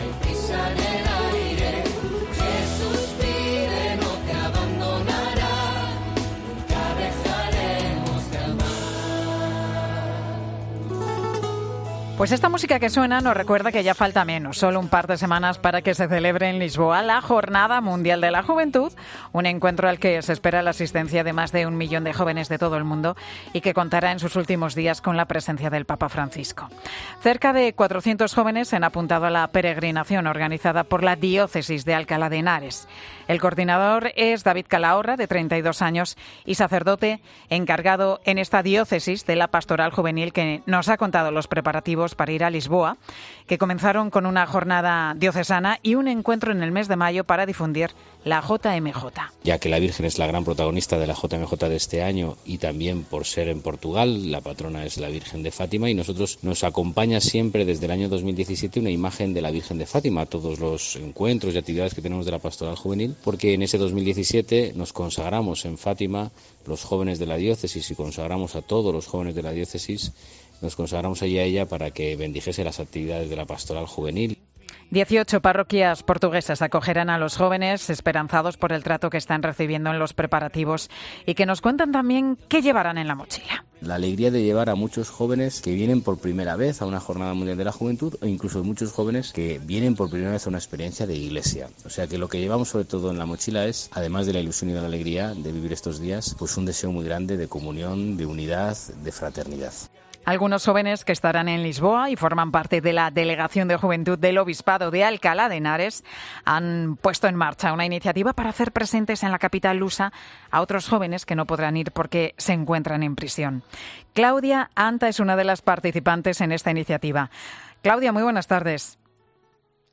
Esta música que suena nos recuerda que ya falta menos, sólo un par de semanas, para que se celebre en Lisboa la Jornada Mundial de la Juventud.